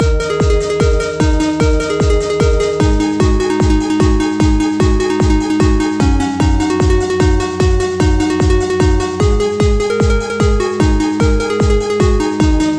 He said it should be a trance-progressive track.